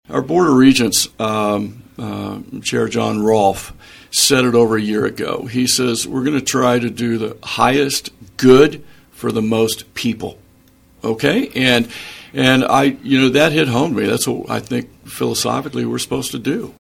Emporia State University President Ken Hush says a transformation is fully underway on campus, and he offered his perspective on KVOE’s Morning Show on Thursday.